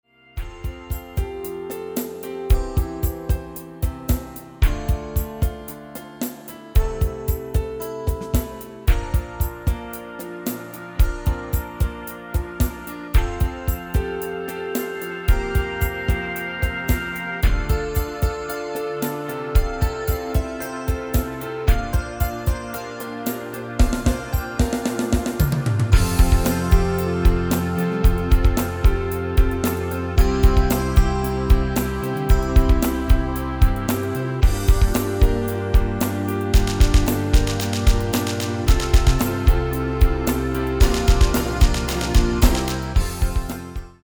Demo/Koop midifile
Genre: Actuele hitlijsten
- GM = General Midi level 1
- Géén vocal harmony tracks